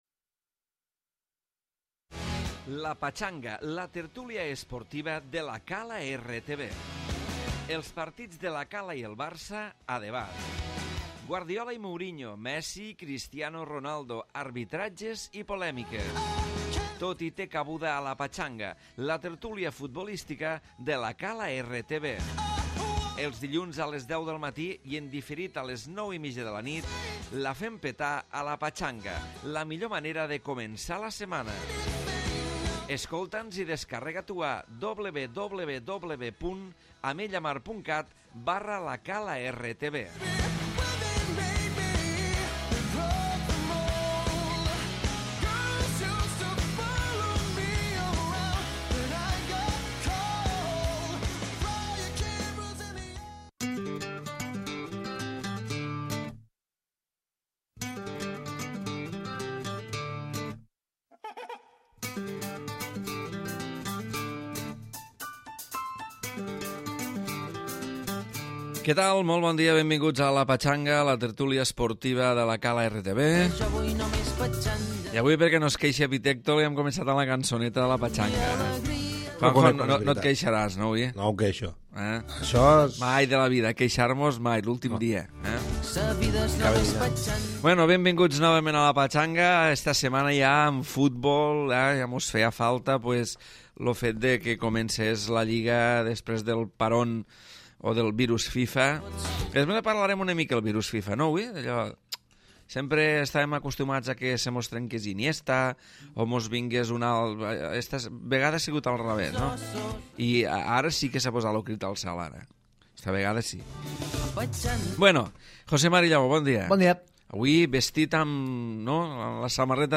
Nova edició de la tertúlia esportiva amb un pes important dels comentaris de la victòria del 1r equip de La Cala davant del líder, l'Olimpic Mora d'Ebre i també sobre la jornada de lliga amb el Barça i el Madrid com a protagonistes.